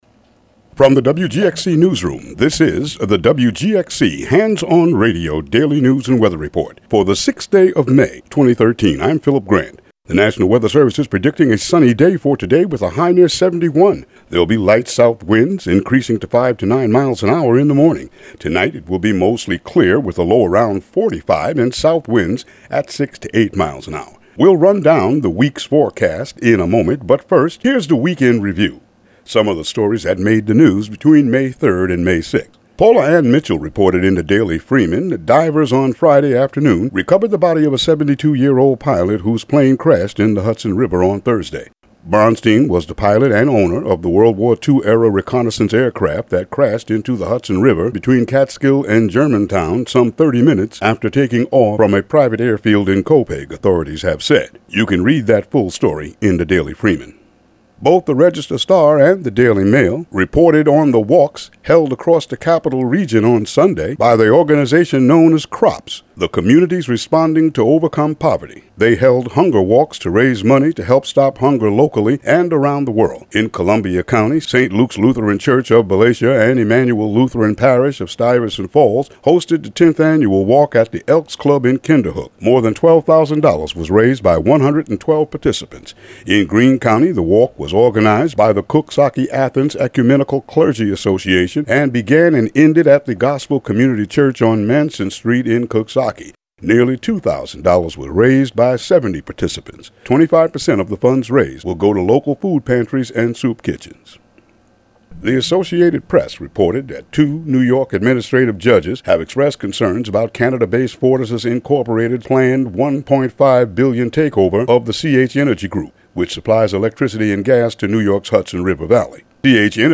Weather and Local headlines for Monday, May 6, 2013.